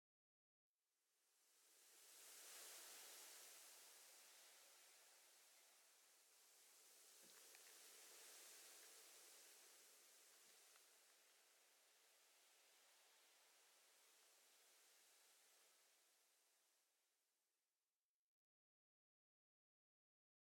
Minecraft Version Minecraft Version snapshot Latest Release | Latest Snapshot snapshot / assets / minecraft / sounds / block / sand / sand19.ogg Compare With Compare With Latest Release | Latest Snapshot
sand19.ogg